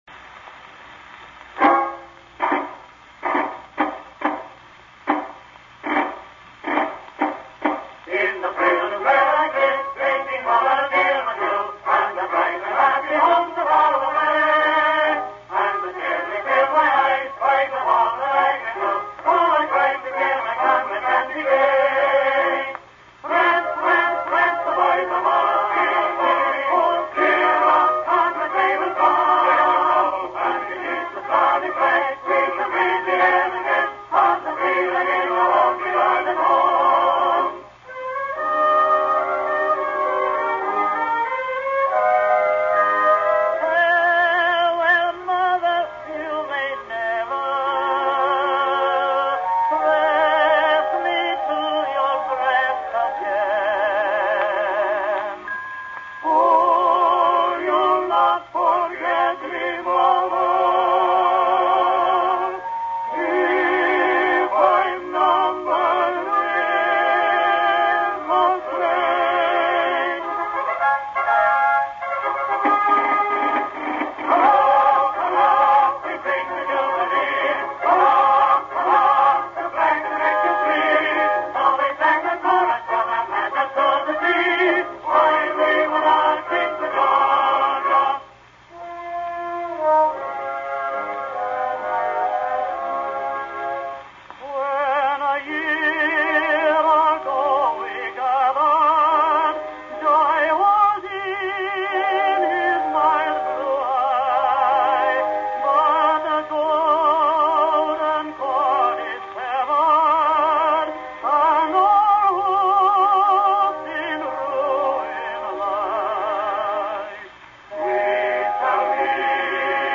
war_songs_victor_male_chorus.mp3